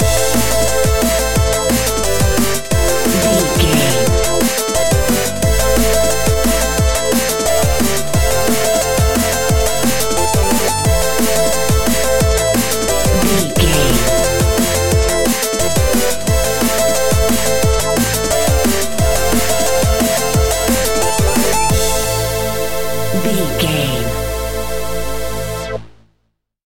Epic / Action
Fast paced
Aeolian/Minor
aggressive
dark
driving
energetic
frantic
futuristic
synthesiser
drum machine
electronic
sub bass
Neurofunk
synth leads